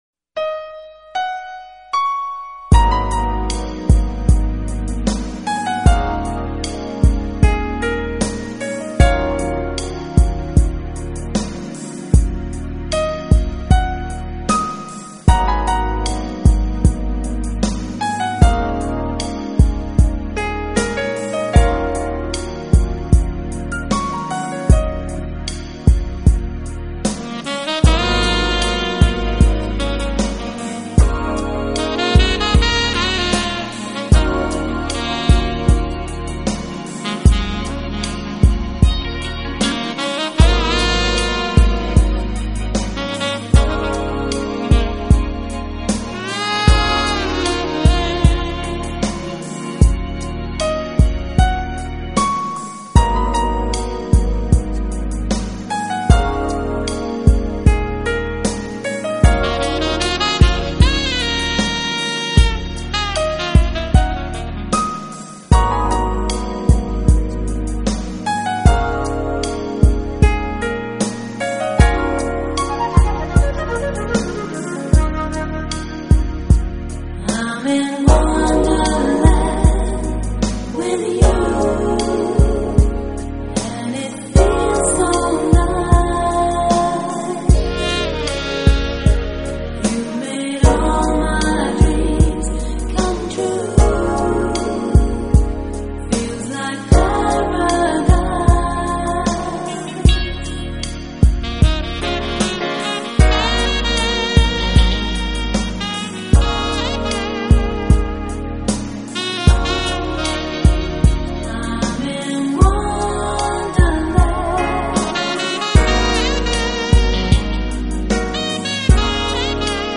类型: Smooth Jazz